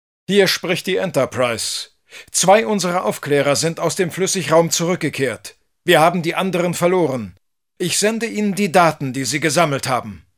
As before, the game features the famous German voices of Captain Jean-Luc Picard, Chancellor Martok and the Borg Queen ...
picture x Die Borg-Königin: